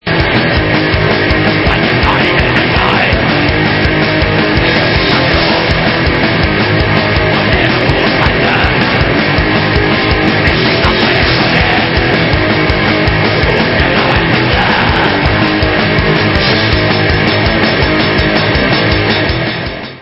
+ 15 YEARS OF SATANIC BLACK METAL // 2008 ALBUM